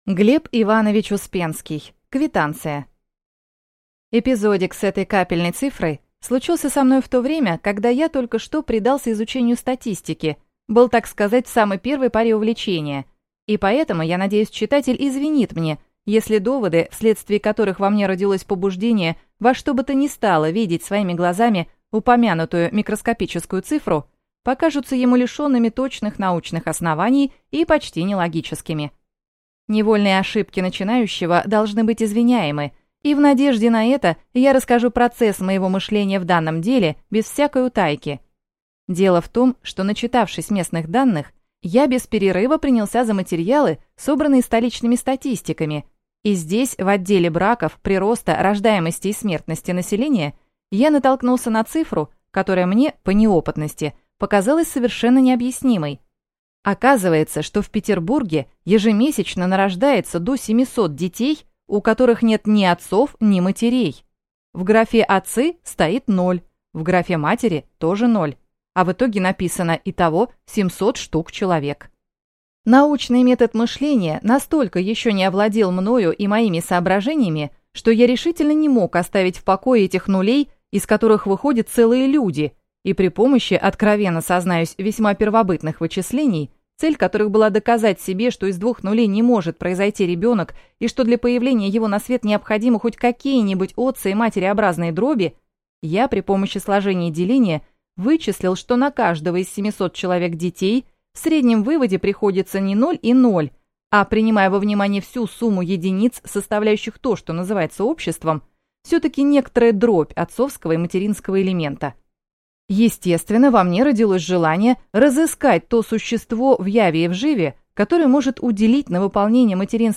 Аудиокнига Квитанция | Библиотека аудиокниг